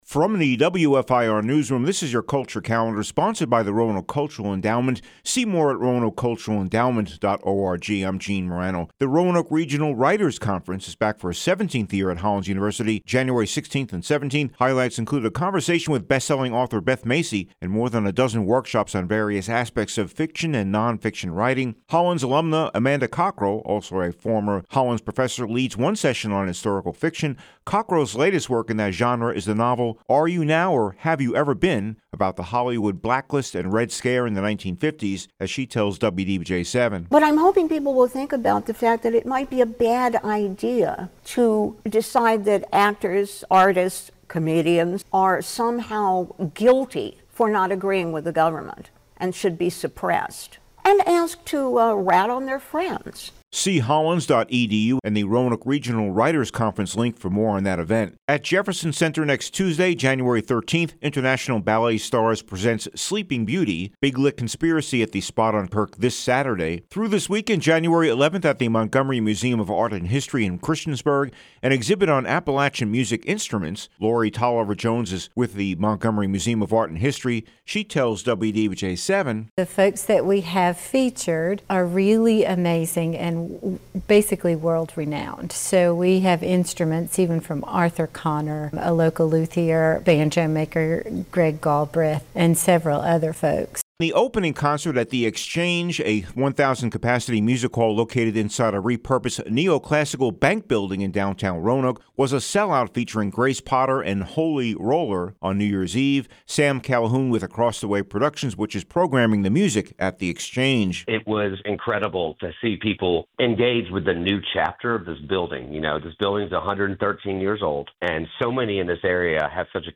From the WFIR News room this is your Culture Calendar – With a look at some of the local events coming up this weekend and in the near future.